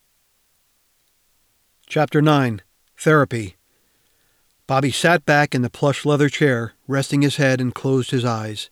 I didn’t need the first step because you have no rumble—which is suspicious…but OK.
I applied Mastering steps two and three, RMS-Normalize and Limiter.
I did cut off one of the three seconds of room tone you supplied at the beginning.
The second patch is applying just a delicate Noise Reduction 6, 6, 6, but technically you don’t need it to pass ACX technical conformance.